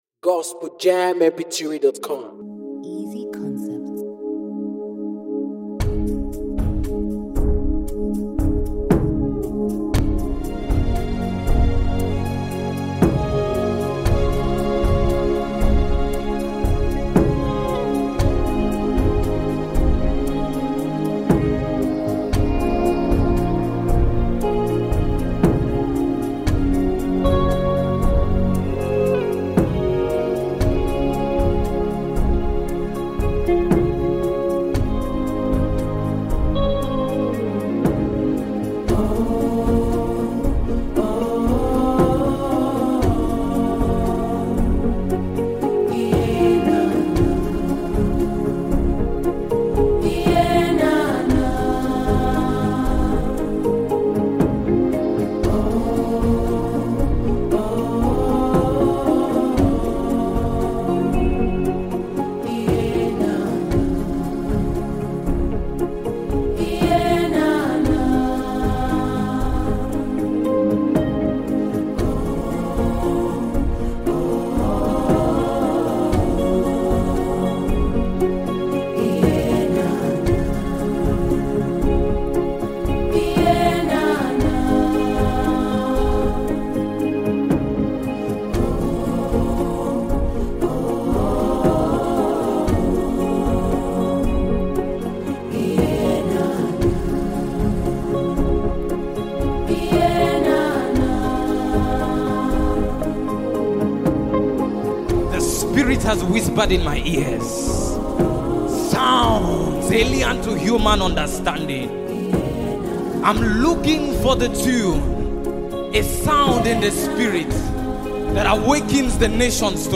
A song off his Live Album